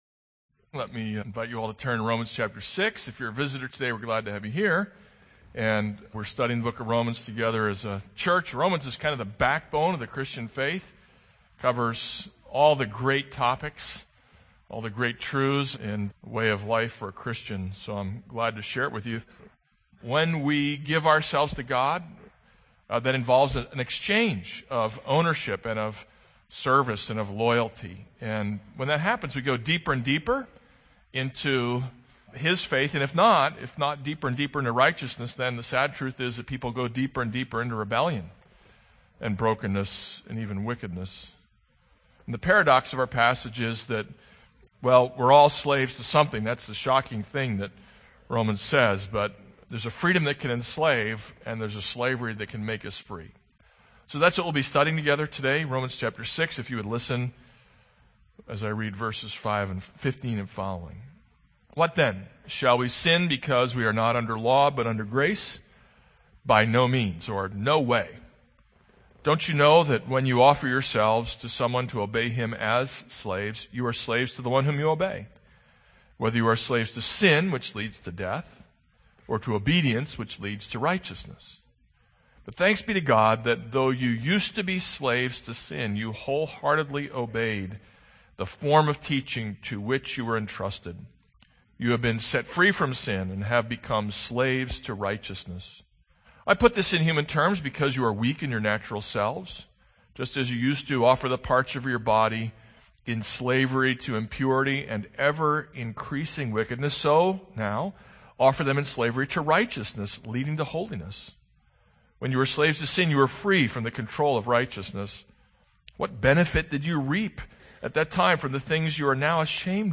This is a sermon on Romans 6:15-23.